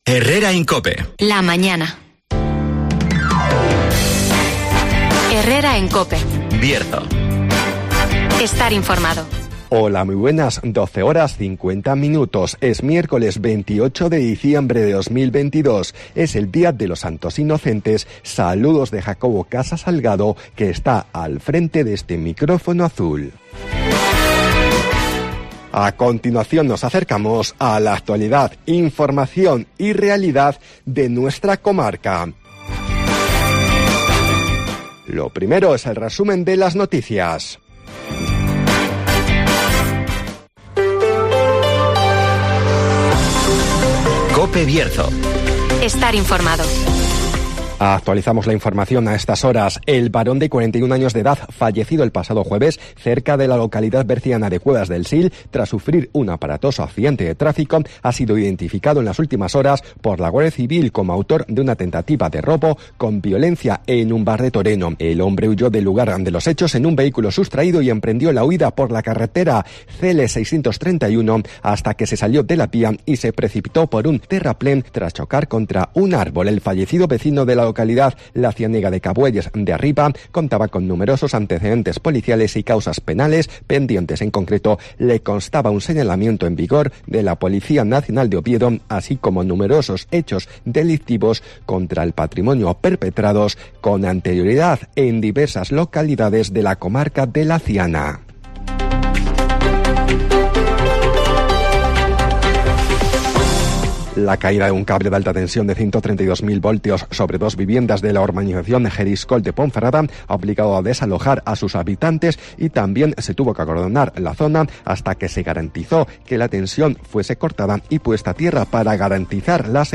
Resumen de las noticias, el tiempo y la agenda.